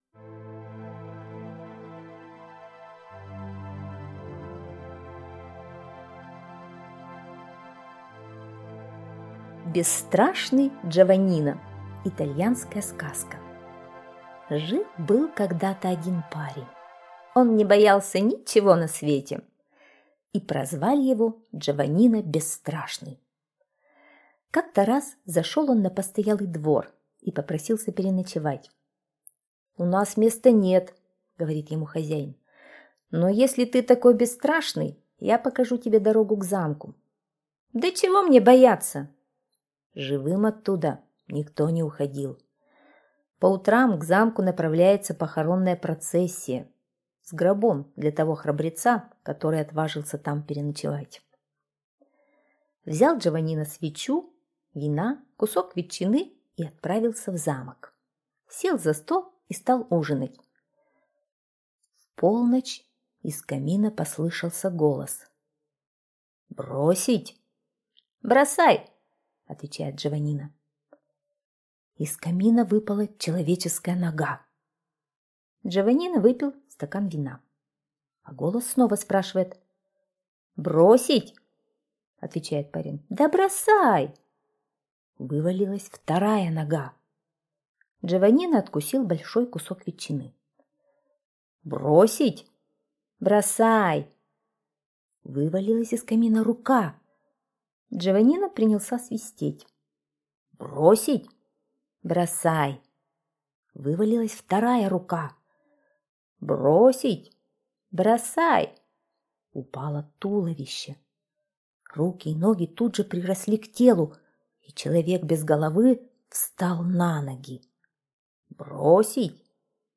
Бесстрашный Джованино - итальянская аудиосказка - слушать онлайн